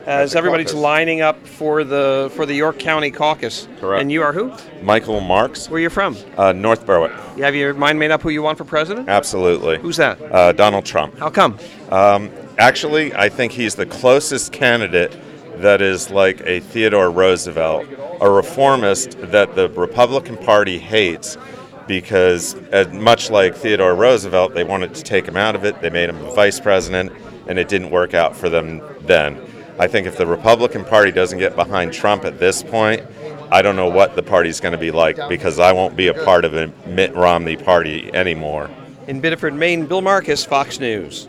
SPEAKS WITH A TRUMP SUPPORTER ON LINE TO REGISTER TO VOTE AT THE YORK COUNTY, MAINE CAUCUS